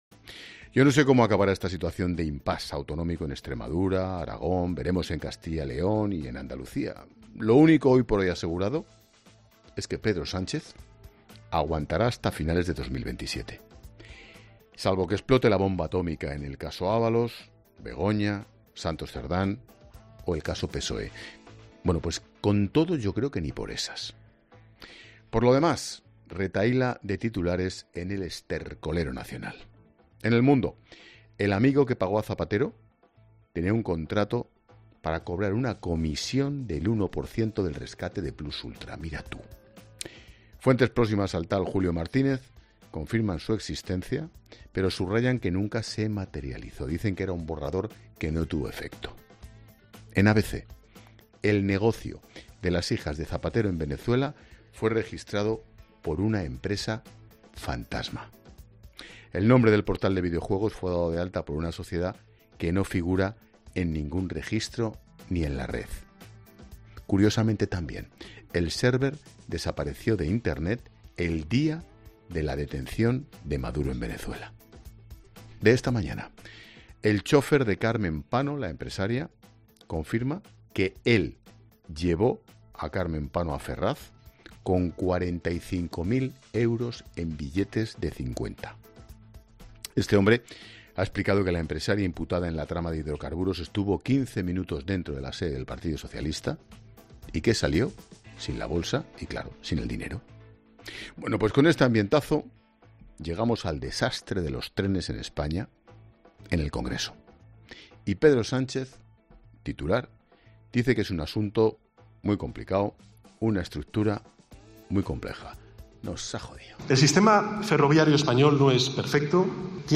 El comunicador Ángel Expósito ha analizado la actualidad política en su programa 'La Linterna', de COPE, centrándose en la crisis ferroviaria y los casos de corrupción.